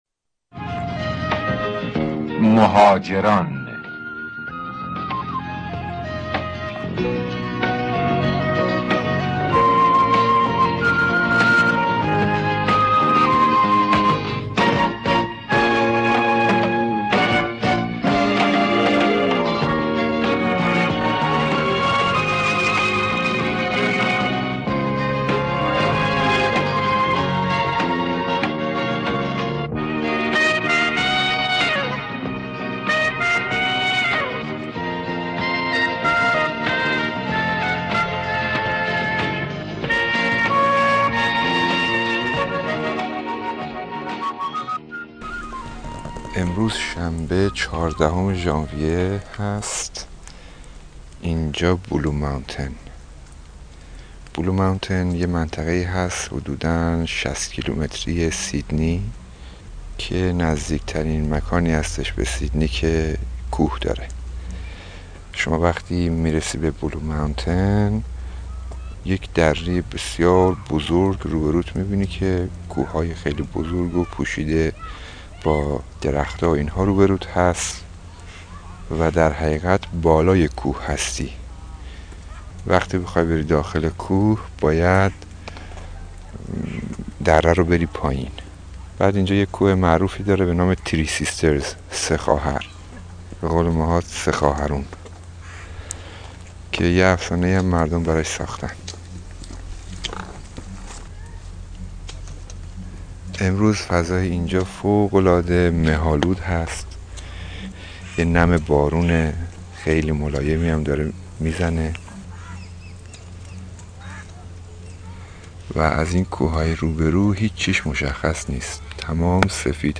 هفتهٔ گذشته به Blue Mountains شرفیاب شدیم. این پادکست، ویدیو و عکس‌ها را آنجا گرفتم: